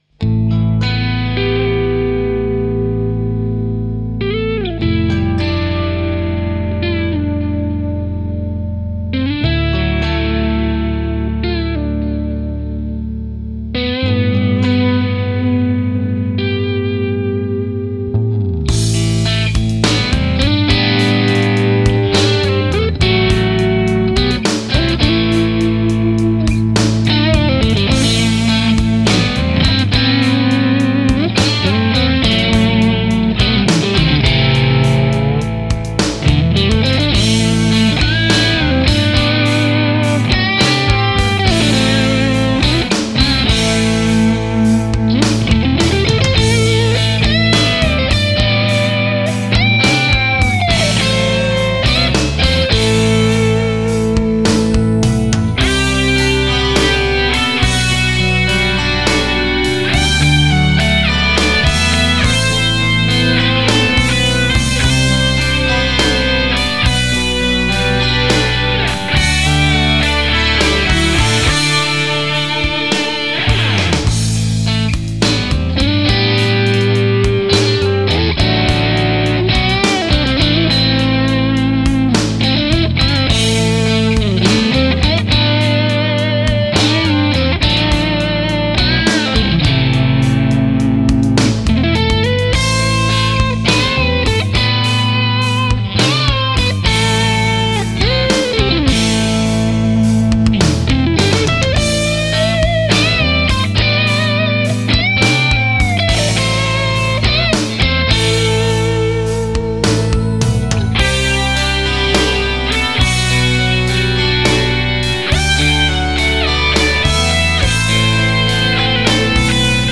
Instrumental, Rock
I really enjoyed recording the verse solo guitar for this one, in particular because of the cool guitar sound that is sort of in between crunchy and distorted and played with the neck pickup in single-coil mode of my 7-string. Also the tapping part of the main heavy solo was quite challenging to get it the way I wanted it.